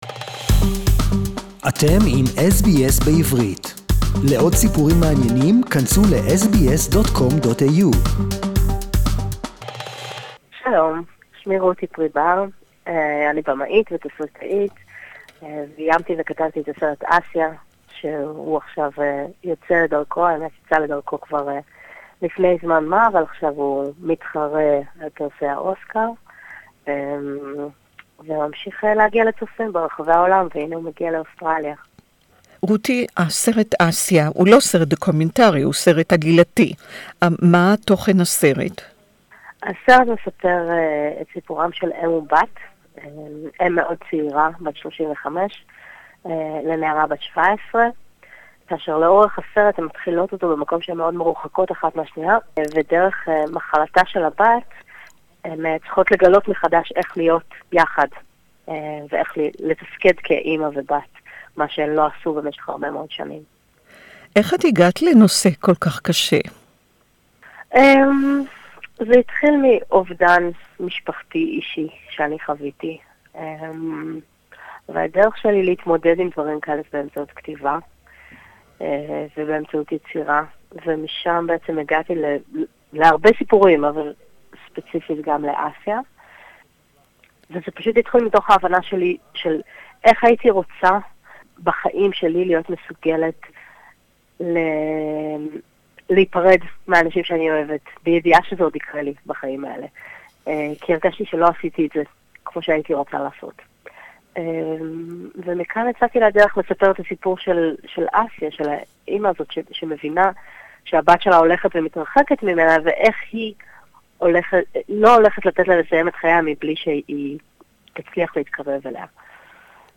Incredible acting and very touching story This interview is in Hebrew